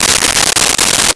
Wrapper Open 02
Wrapper Open 02.wav